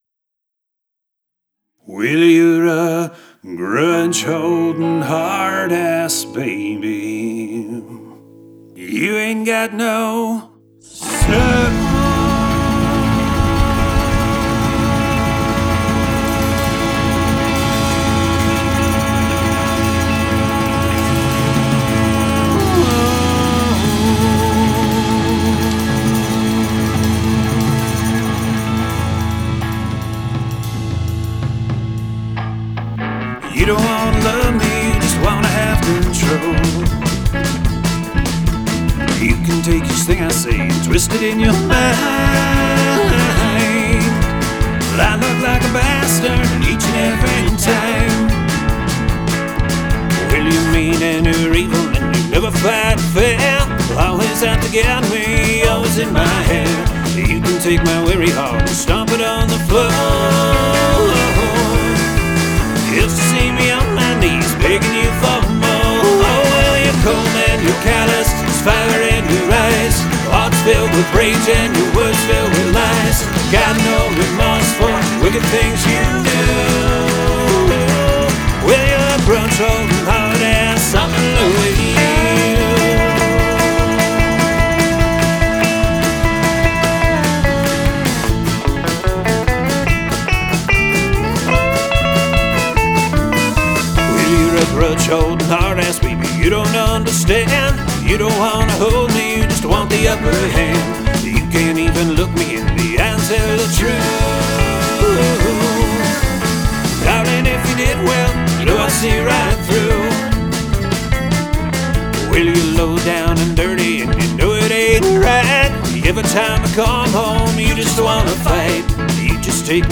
bass guitar
lead guitar
fiddle, mandolin, acoustic guitar,vocals
drums, vocals